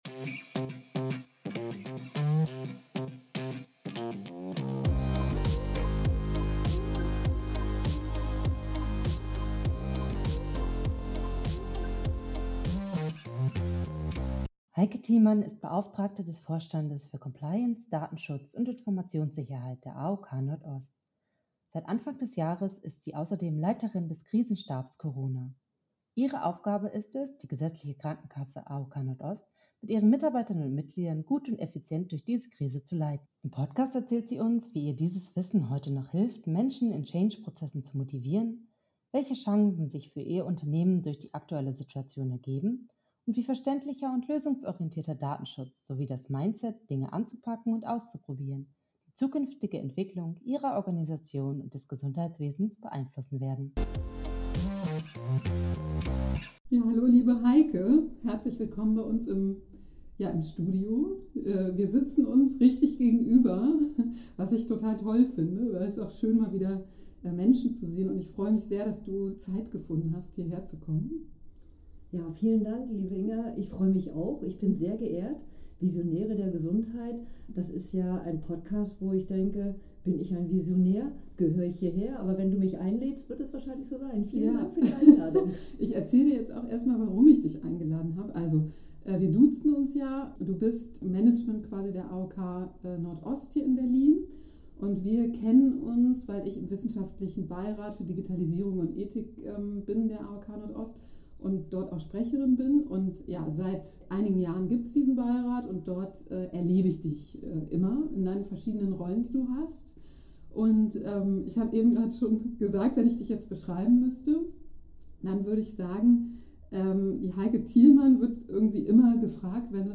Leider war es sehr warm zum Zeitpunkt der Aufnahme, so dass wir Fenster und Türen geöffnet lassen mussten und der Podcast so etwas leiser als gewöhnlich aufgenommen wurde.